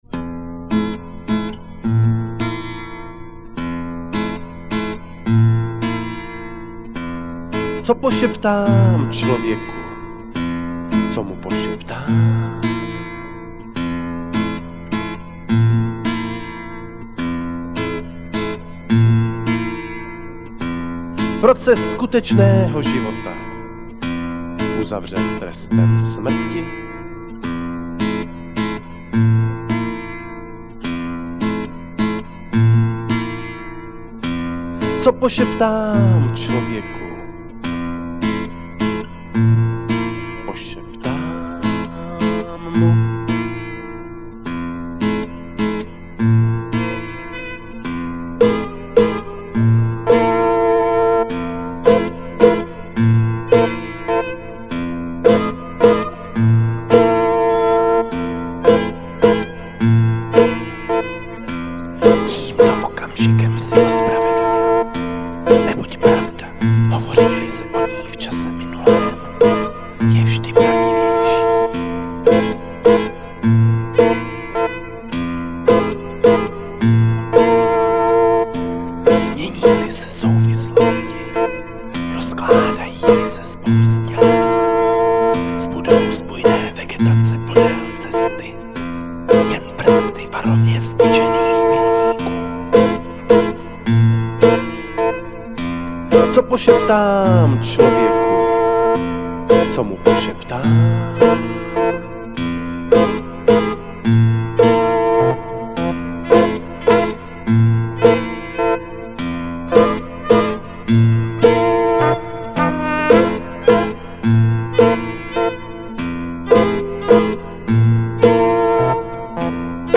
art-rock/mininal/alternatíva z Valašska.